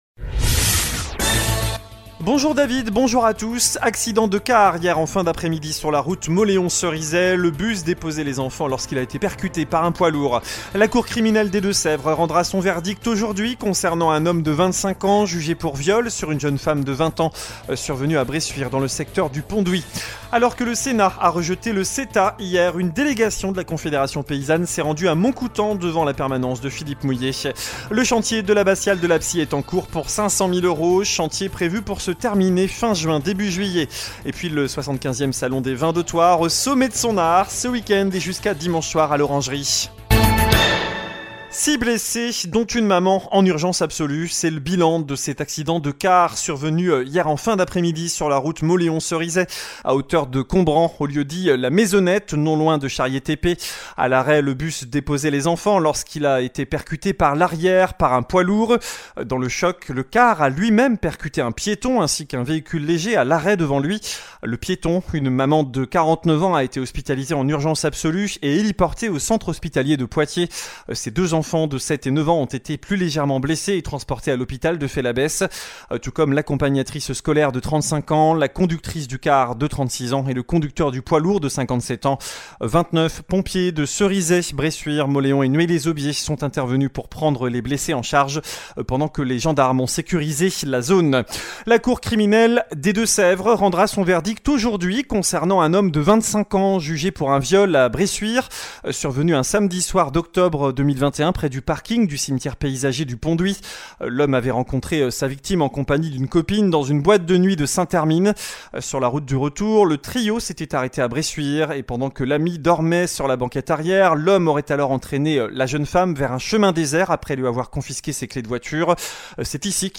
Journal du vendredi 22 mars (midi)